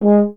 023_FH G#3 SCF.wav